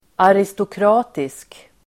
Ladda ner uttalet
aristokratisk adjektiv, aristocratic Uttal: [aristokr'a:tisk] Böjningar: aristokratiskt, aristokratiska Definition: som är typisk för aristokratin, förnäm (being typical of the aristocracy, lofty, superior)
aristokratisk.mp3